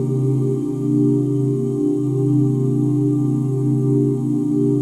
OOHC SUS13.wav